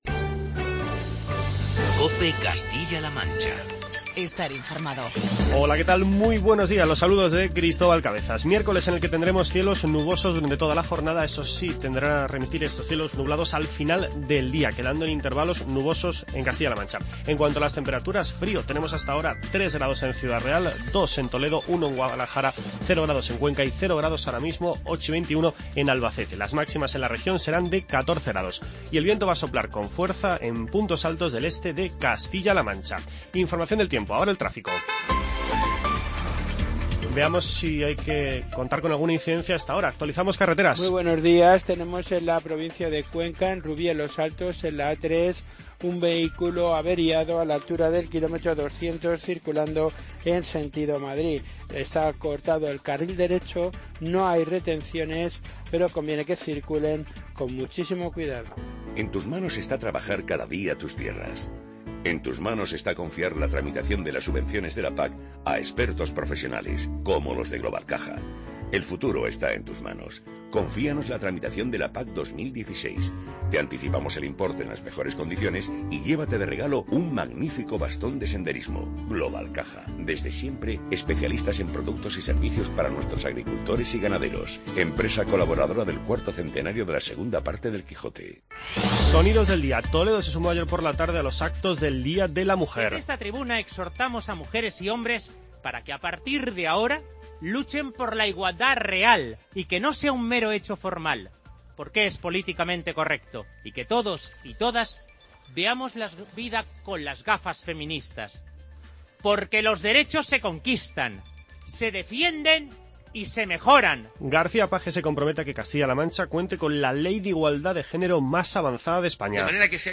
Informativo regional
Además, escuchamos un fragmento del manifiesto que se leyó ayer ante la puerta del Ayuntamiento de Toledo con motivo del Día Internacional de la Mujer.